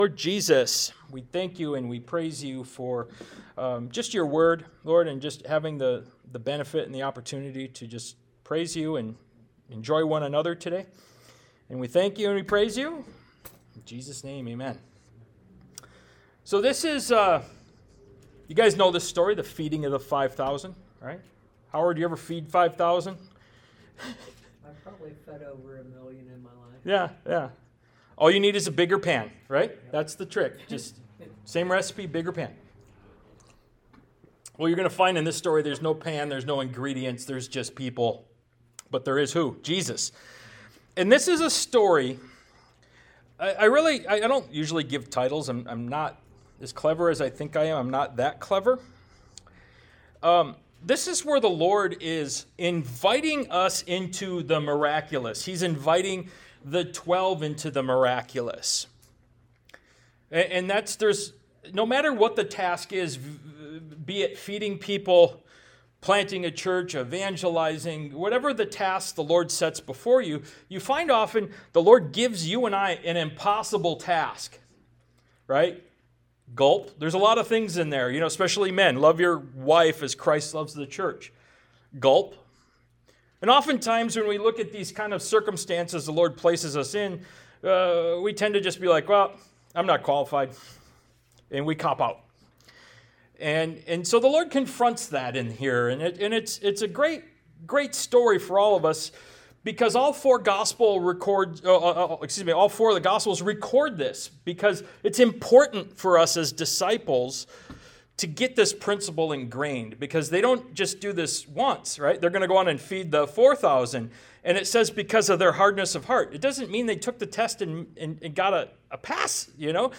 Ministry of Jesus Service Type: Sunday Morning « Calvary Chapel Core Values “Get Out of the Boat” Ministry of Jesus Part 37 »